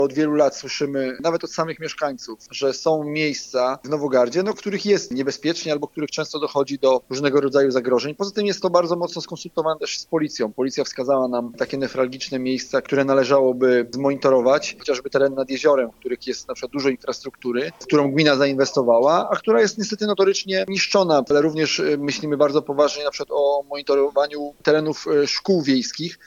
Burmistrz Michał Wiatr wierzy, że będzie dzięki temu po prostu bezpieczniej,